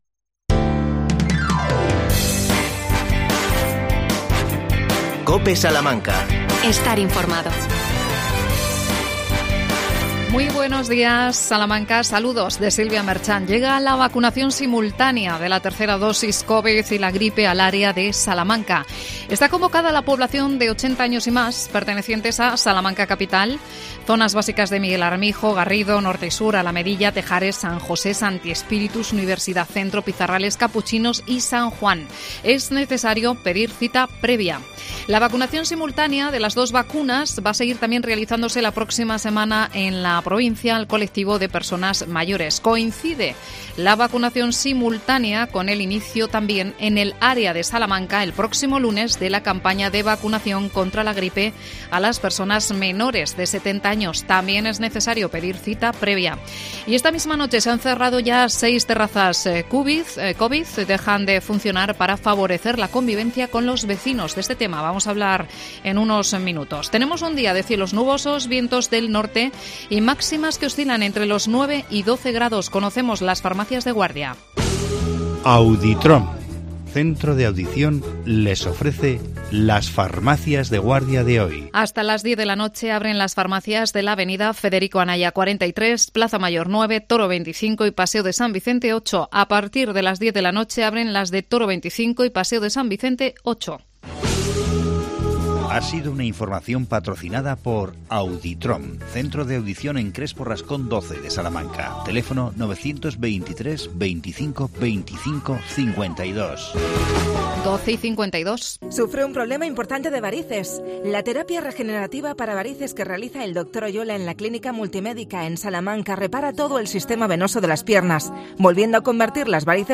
AUDIO: Entrevistamos